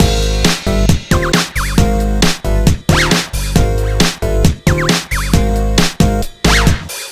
Jazz Funk X Full.wav